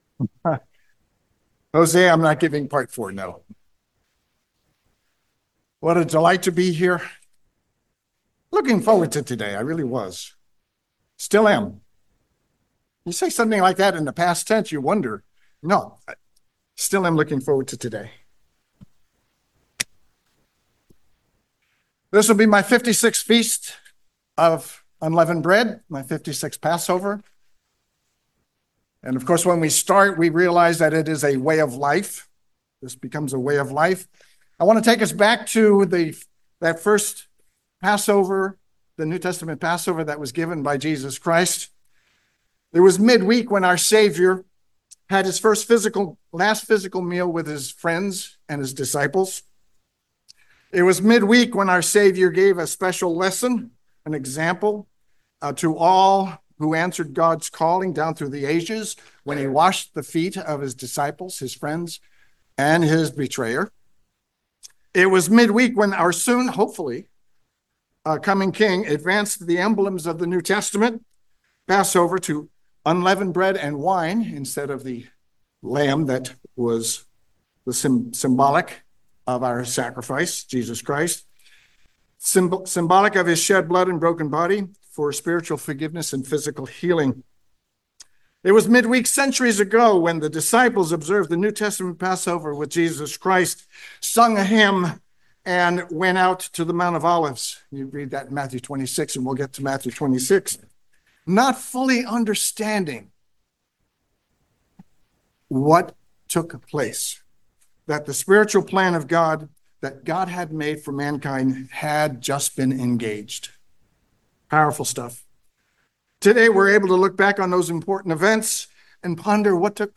Given in San Francisco Bay Area, CA